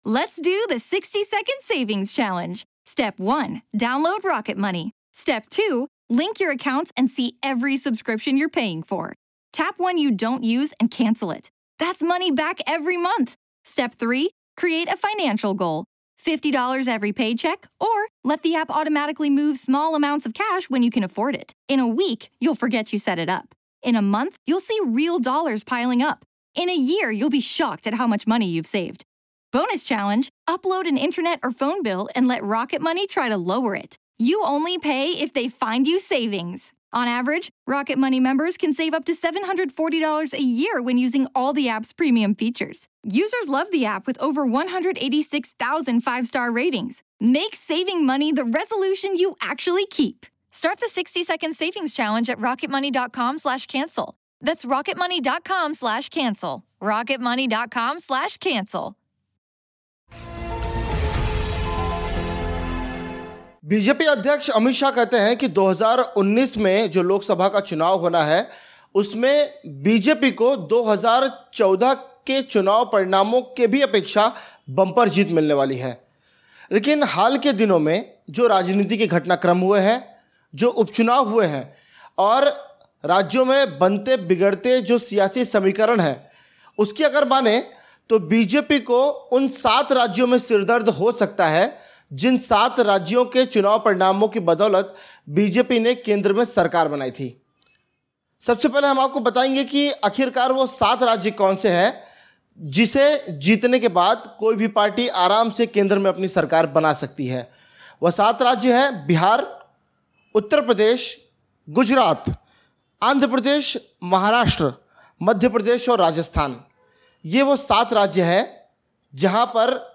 News Report / वो सात राज्य जो 2019 के लोकसभा चुनाव में भाजपा को दे सकते है झटके